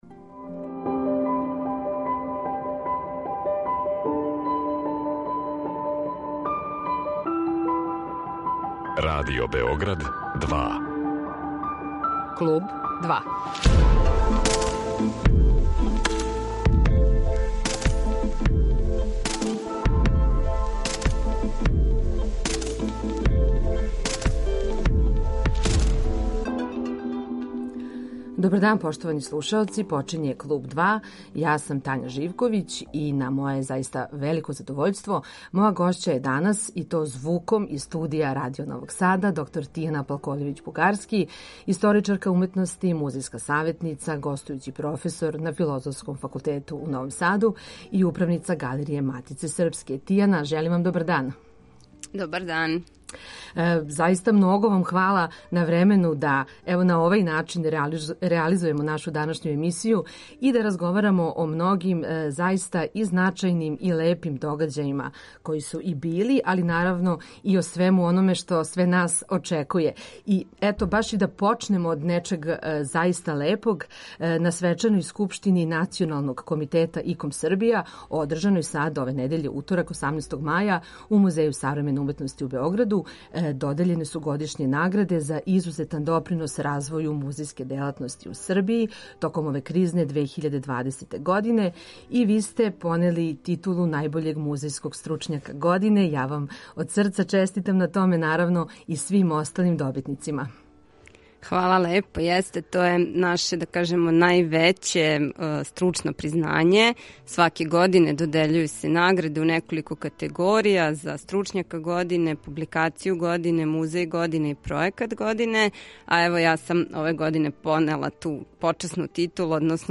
Повод за данашњи разговор је награда за најбољег музејског стручњака у 2020. години, које додељује Национални комитета Међународног савета музеја.